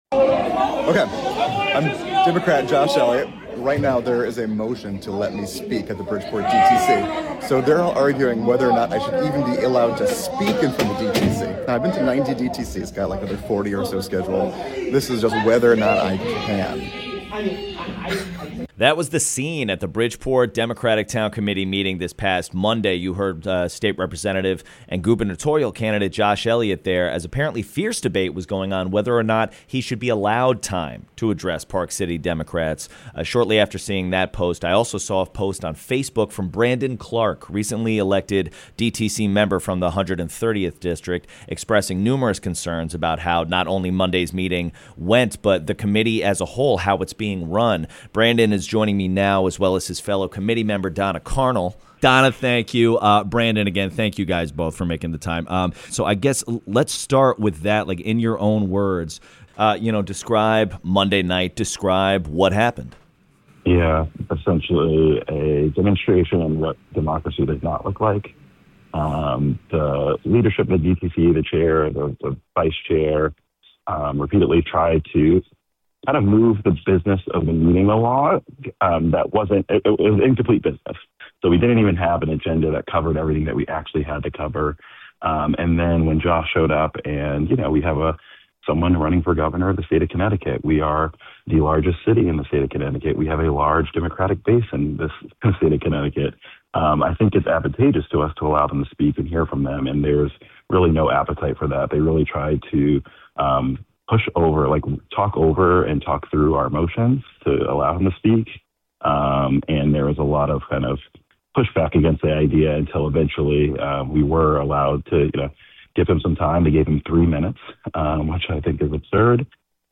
spoke with two DTC members to get the scoop!